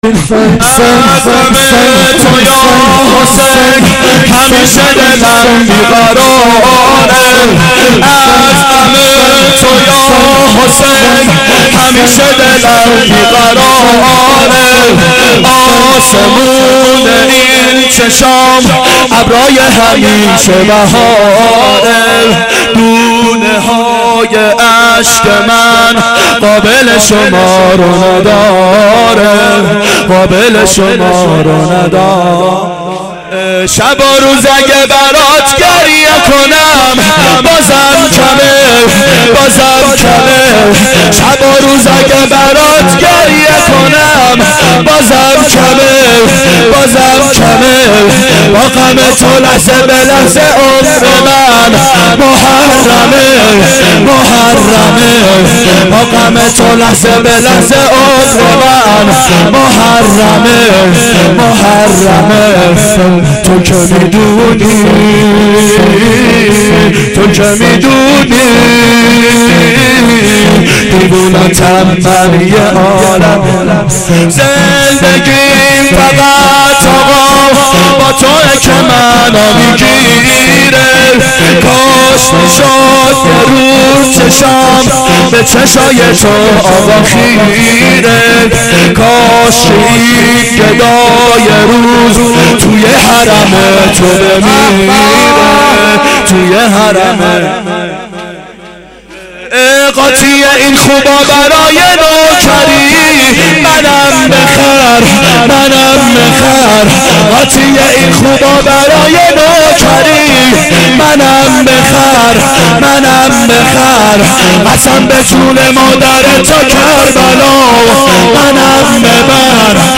شب عاشورا 1390 هیئت عاشقان اباالفضل علیه السلام